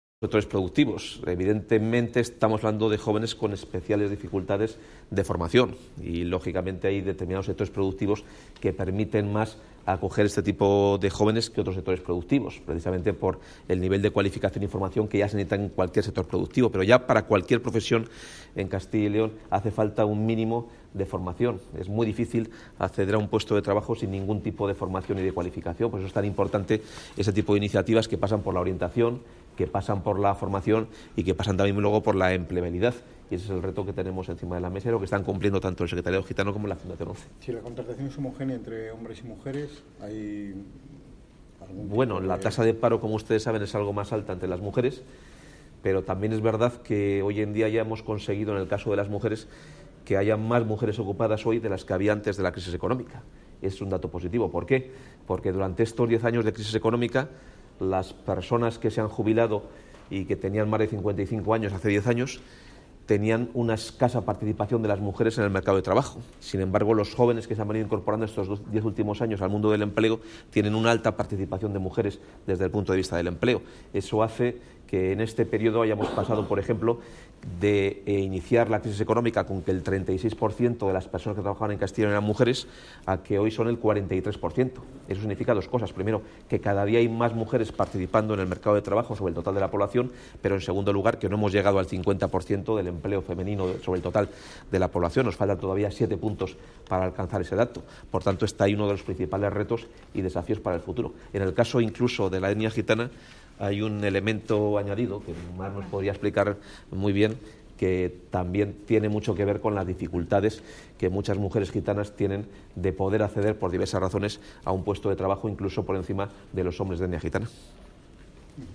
El consejero de Empleo ha participado en la jornada ‘Jóvenes y + … de Castilla y León’ que ha sido organizada por la...
Consejero de Empleo, sobre la jornada del Programa Operativo de Empleo Juvenil.